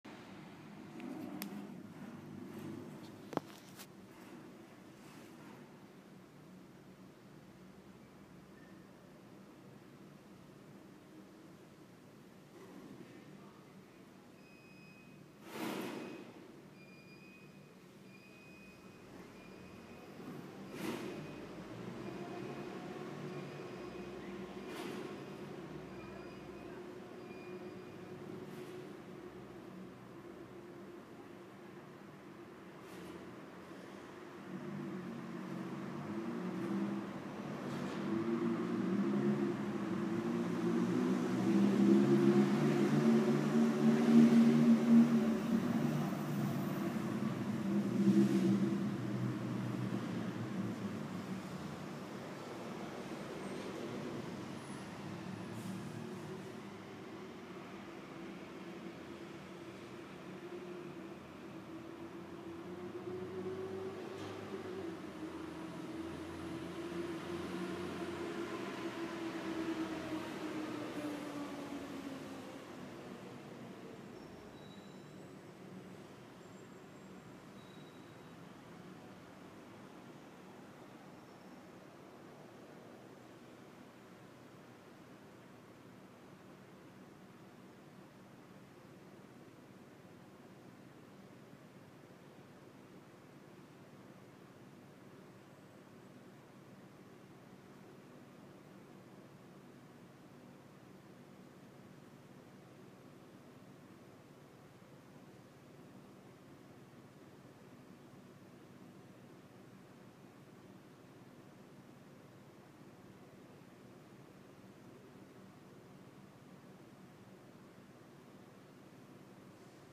Eine atemberaubende Metropole, brüllend laut, voll, rasant, kurz: unbeschreiblich.
av-pueyerredon.mp3